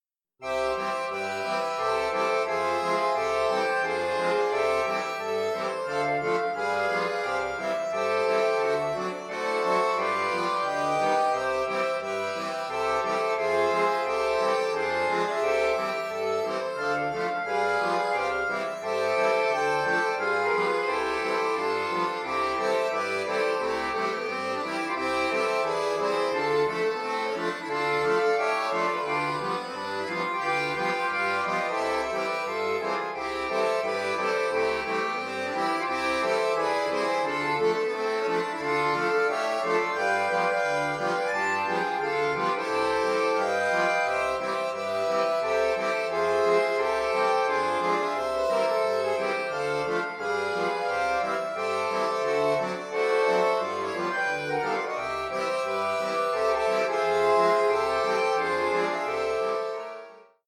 Weihnachts-Evergreen aus dem englischsprachigen Raum